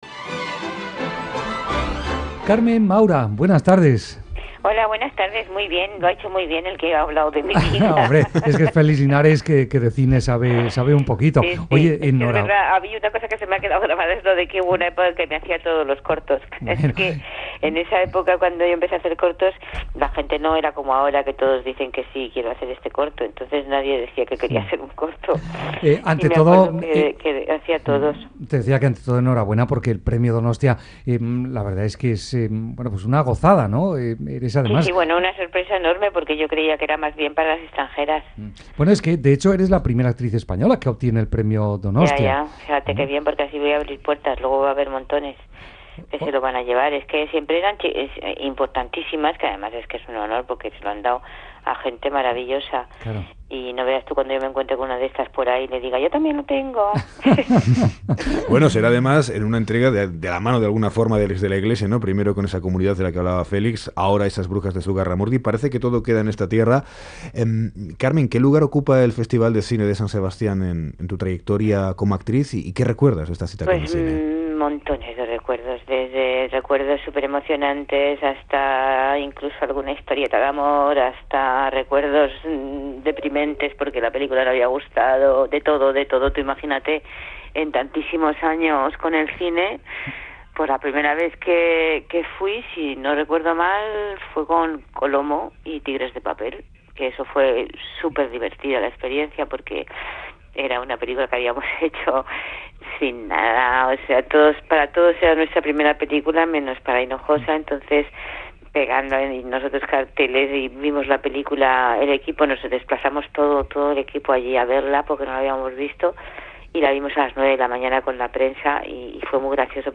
Entrevista a Carmen Maura, Premio Donostia 2013 | Zinemaldia
Carmen Maura recibirá el Premio Donostia del Festival de cine de San Sebastian 2013 en reconocimiento a su carrera. Entrevista en 'Graffiti', de Radio Euskadi.